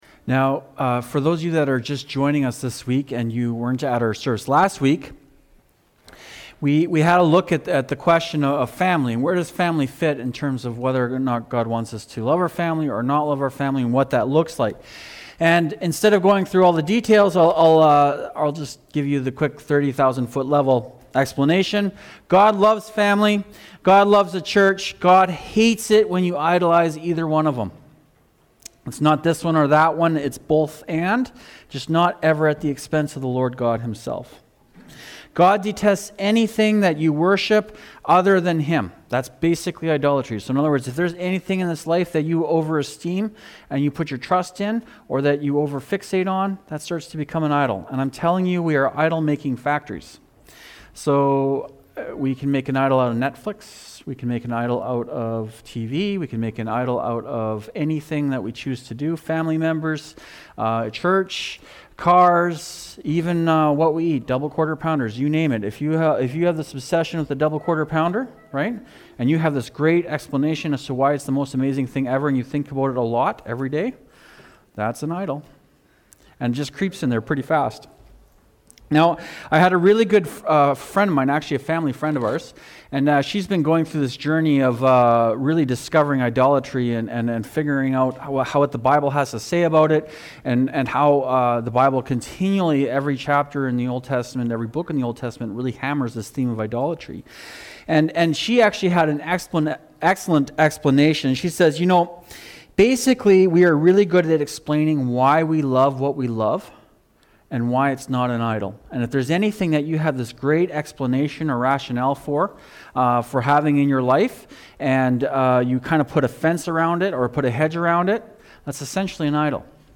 July-5-Sermon-audio.mp3